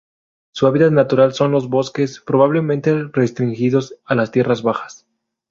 Pronunciado como (IPA)
/pɾoˌbableˈmente/